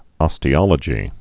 (ŏstē-ŏlə-jē)